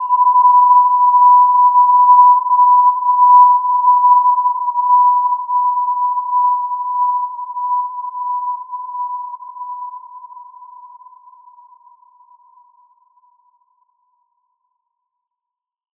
Gentle-Metallic-3-B5-p.wav